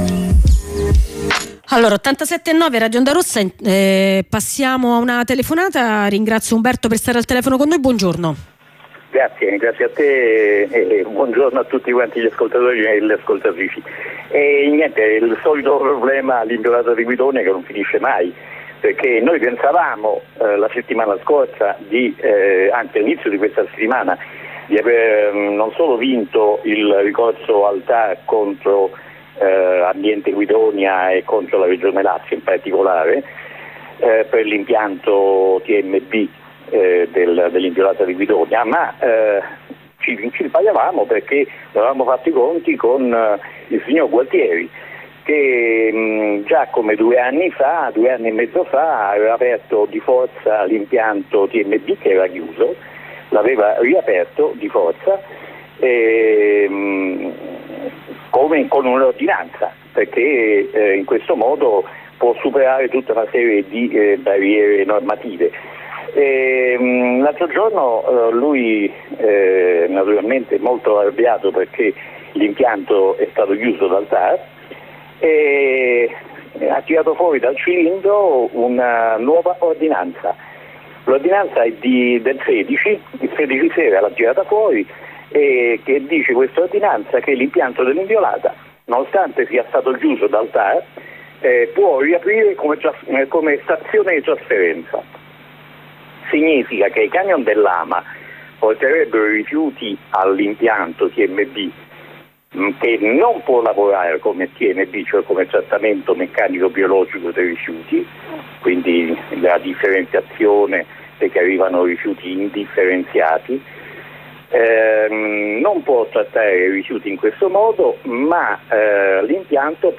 Ne parliamo con un compagno del Comitato per il Risanamento Ambientale (CRA)